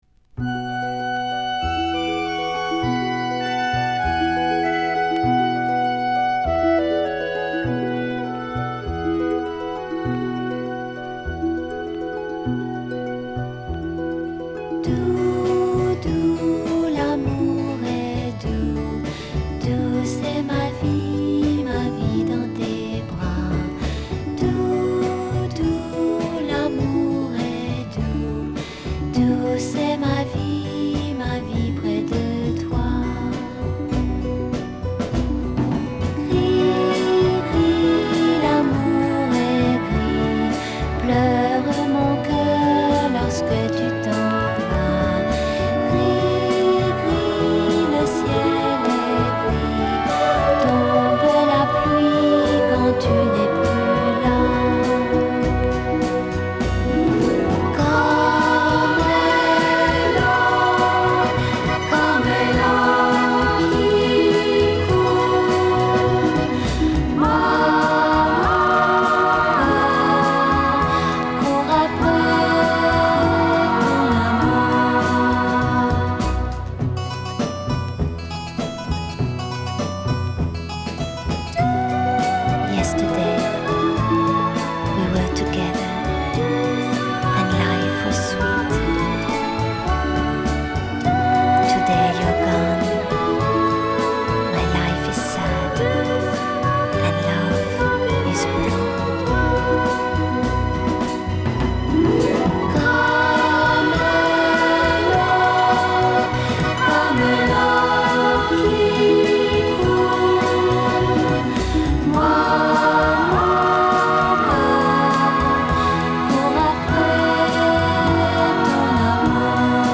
Genre: Pop, Vocal, Easy Listening